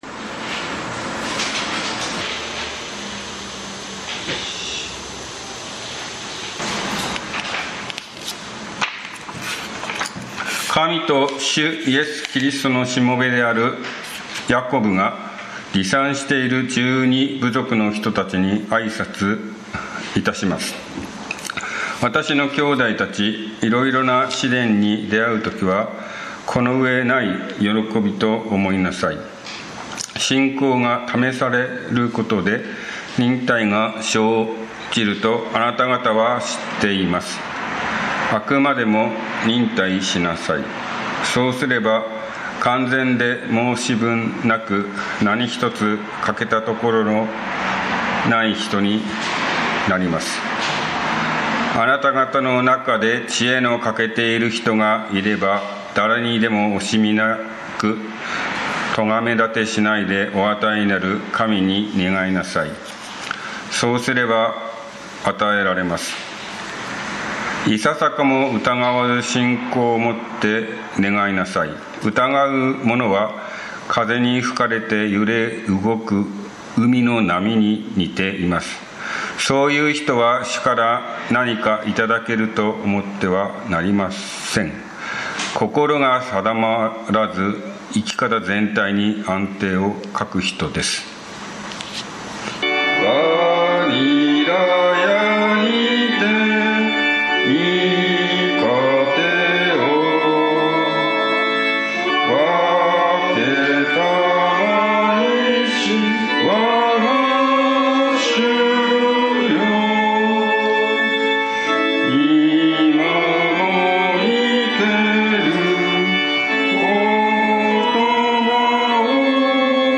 試練に出会うとは 宇都宮教会 礼拝説教
栃木県鹿沼市のプロテスタント教会。